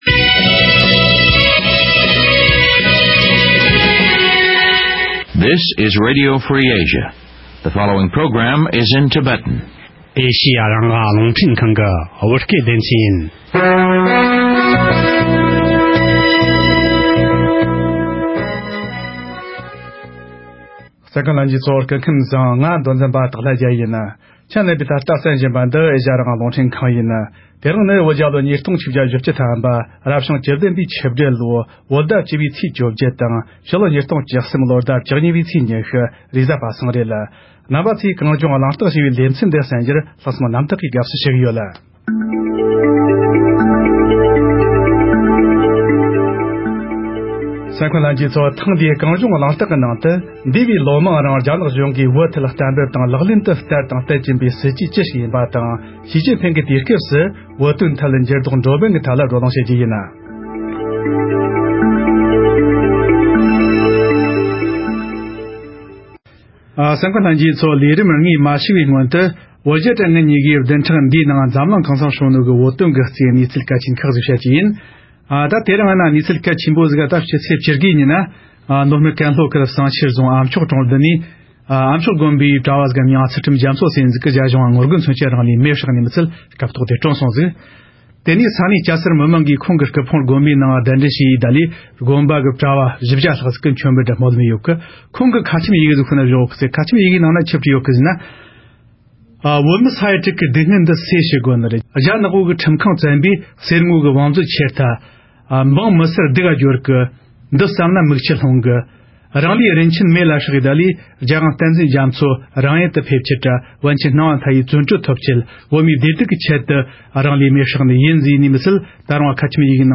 ༄༅། །ཐེངས་འདིའི་༼གངས་ལྗོངས་གླེང་སྟེགས༽ཞེས་པའི་ལེ་ཚན་ནང་། འདས་པའི་ལོ་མང་རིང་རྒྱ་ནག་གཞུང་གིས་བོད་ཐད་གཏན་འབེབས་དང་ལག་ལེན་དུ་བསྟར་དང་ བསྟར་བཞིན་པའི་སྲིད་ཇུས་ཅི་ཞིག་ཡིན་པ་དང་། ཞི་ཅིན་ཕེན་དུས་རབས་སུ་བོད་དོན་ཐད་འགྱུར་ལྡོག་འགྲོ་མིན་སྐོར་བགྲོ་གླེང་ཞུས་པ་ཞིག་གསན་རོགས་གནང་།།